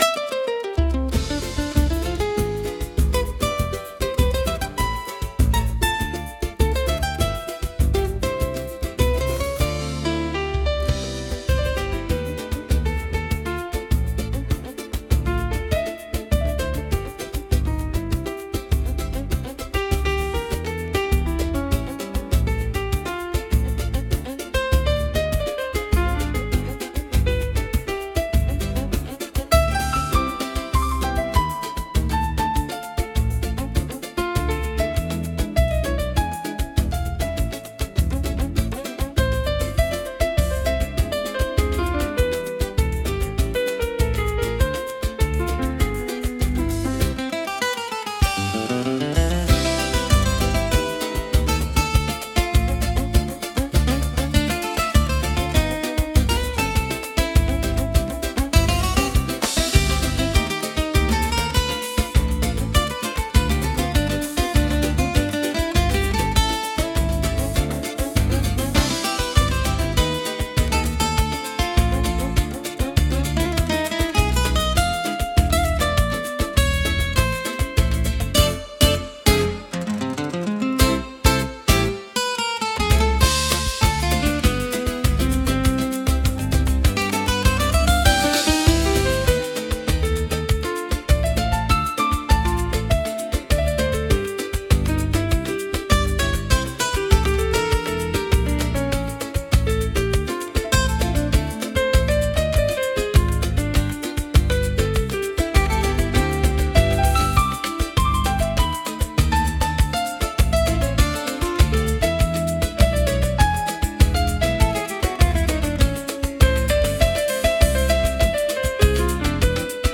música e arranjo: IA) instrumental